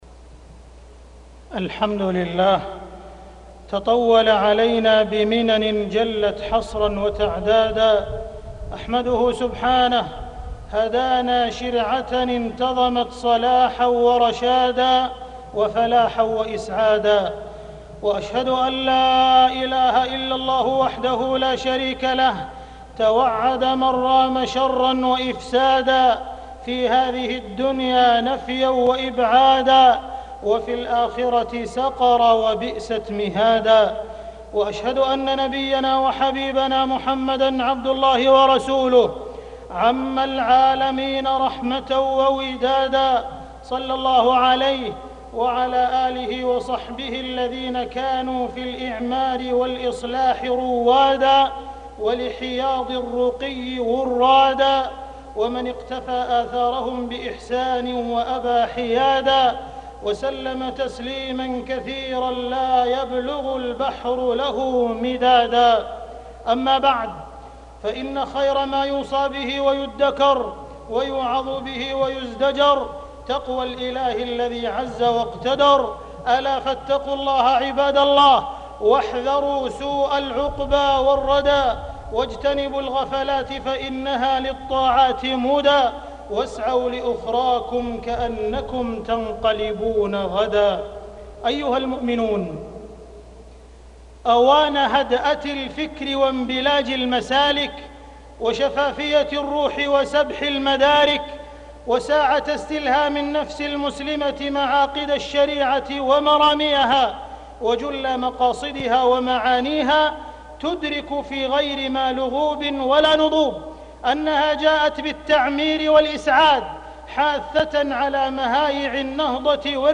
تاريخ النشر ١٧ صفر ١٤٢٧ هـ المكان: المسجد الحرام الشيخ: معالي الشيخ أ.د. عبدالرحمن بن عبدالعزيز السديس معالي الشيخ أ.د. عبدالرحمن بن عبدالعزيز السديس تحذير العباد من ضروب الفساد